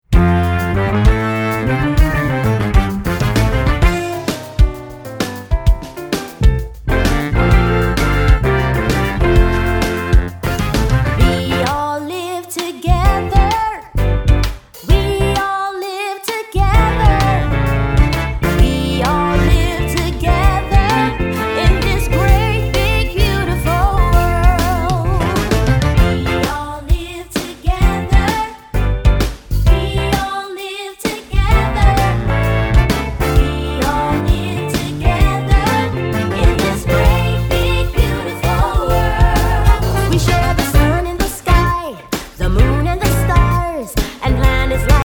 doo-wop and 80s R&B inspired
All tracks except Radio Edits include scripted dialogue.